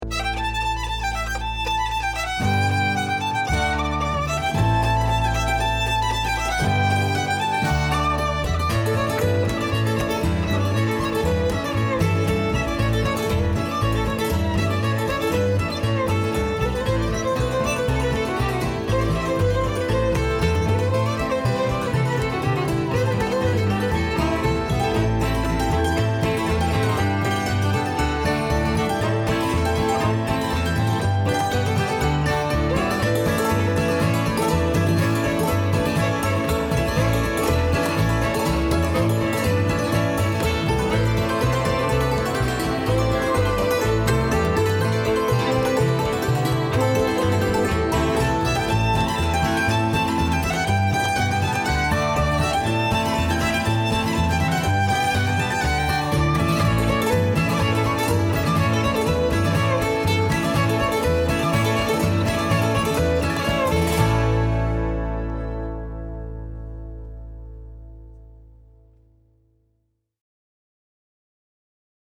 hammered dulcimer & vocals
guitar, banjo, background vocals
bass, keyboard
fiddle & mandolin